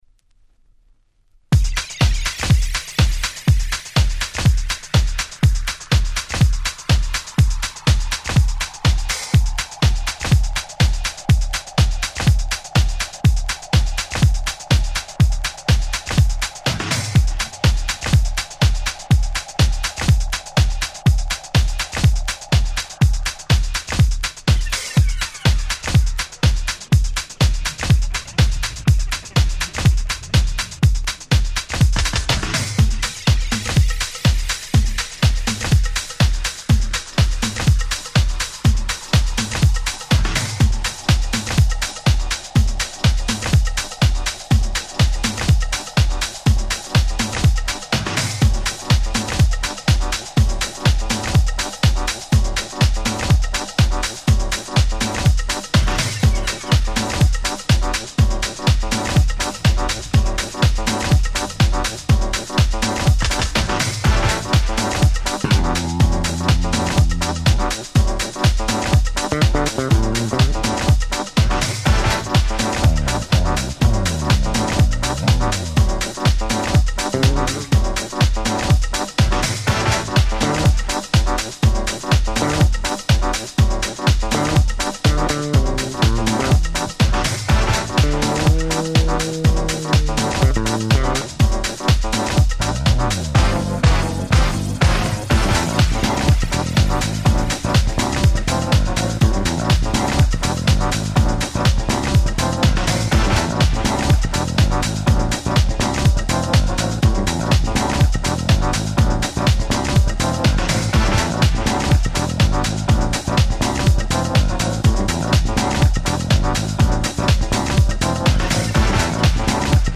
原曲に大変忠実、原曲の良さを一切損なっておりません！